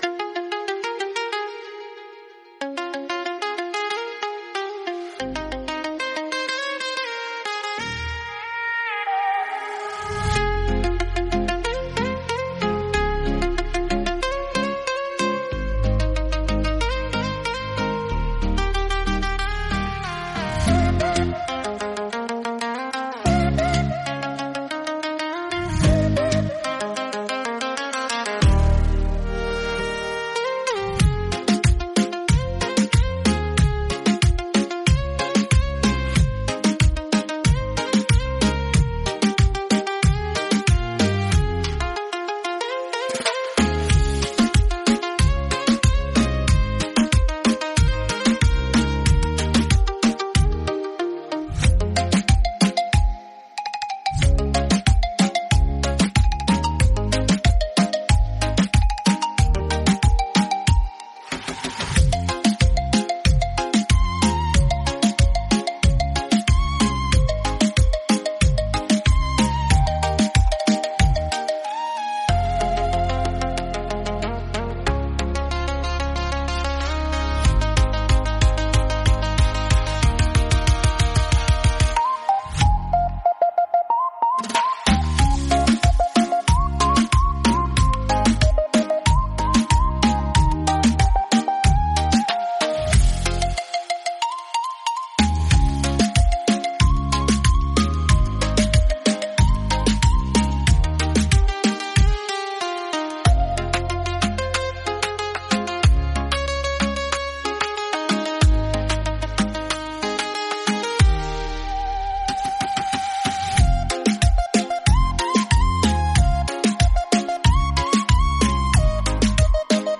Beat Reggaeton Instrumental
Acapella e Cori Reggaeton Inclusi
Fm